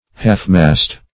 Half-mast \Half"-mast`\ (-m[.a]st`), n.